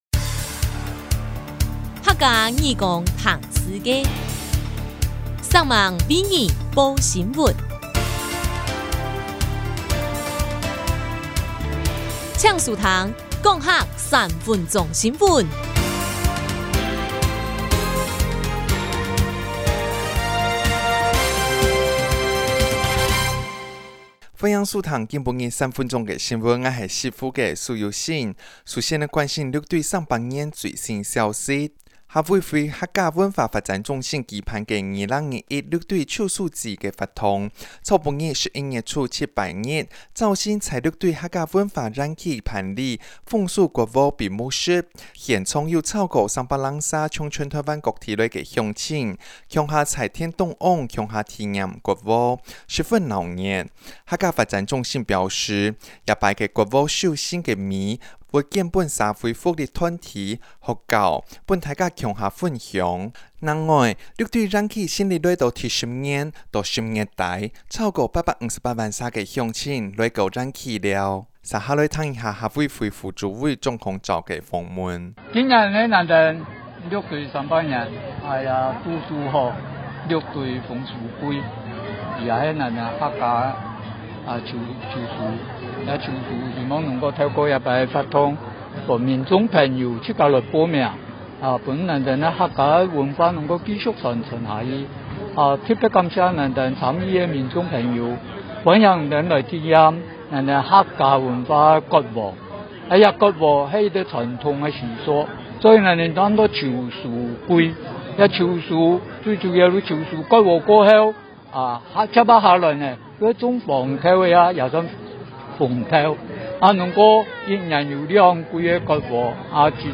1107六堆園區秋收祭割禾閉幕式新聞.mp3